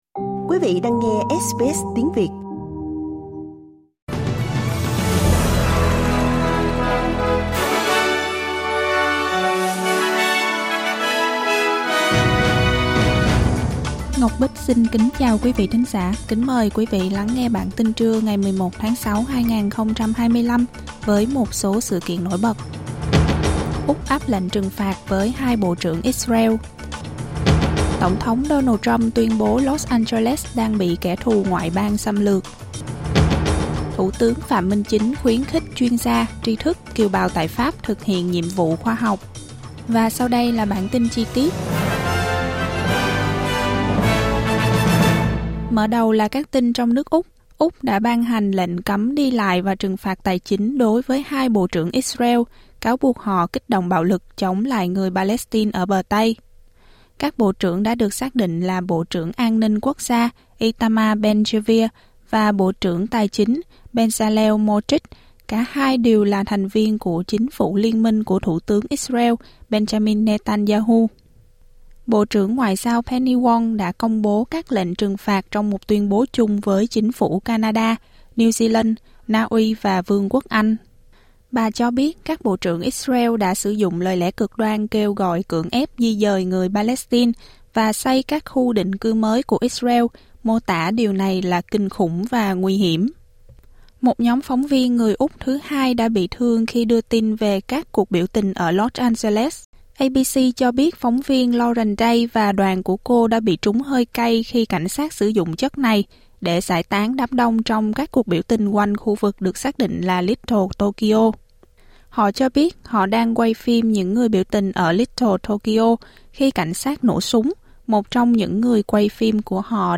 Vietnamese news bulletin Source: AAP